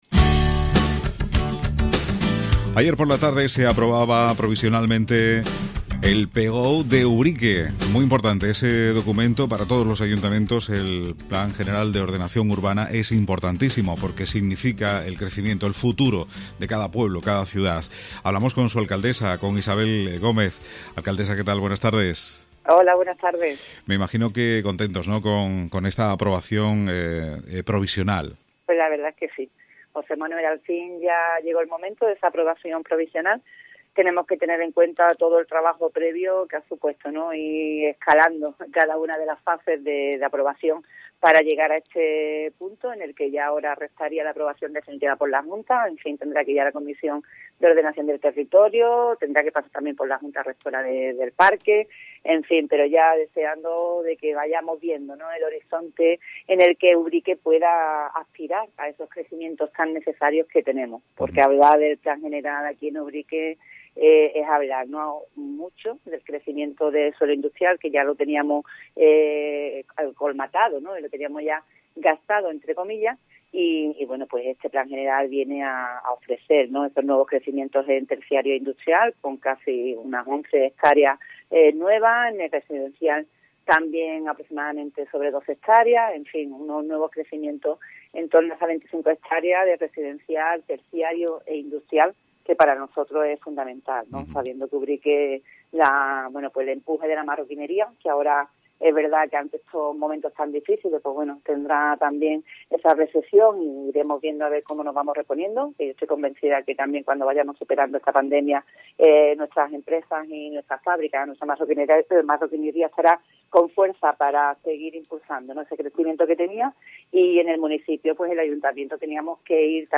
Isabel Gómez, Alcaldesa de Ubrique habla sobre el PGOU